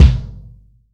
Index of /90_sSampleCDs/AKAI S-Series CD-ROM Sound Library VOL-3/ROCK KIT#1
ROCK CKIK2.wav